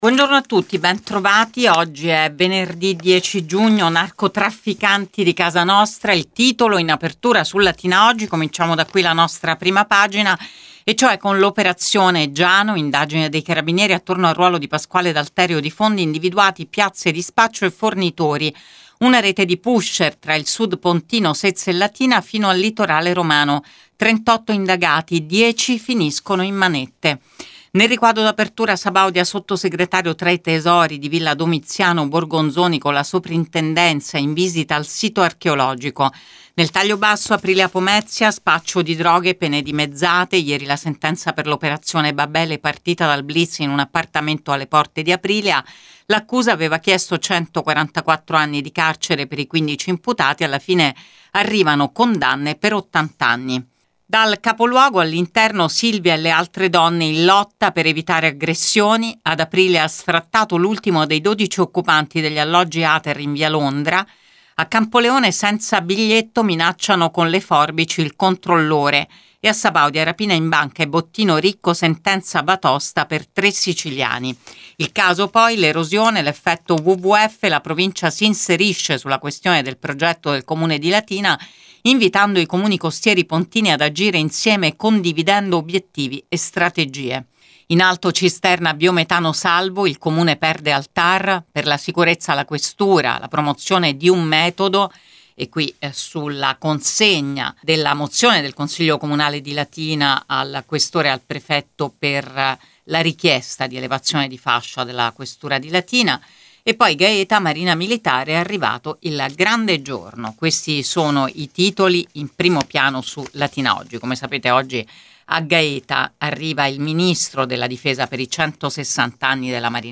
LATINA – Qui trovate Prima Pagina, ora solo in versione web, per dare uno sguardo ai titoli di Latina Editoriale Oggi e Il Messaggero Latina. (audio dopo la pubblicità)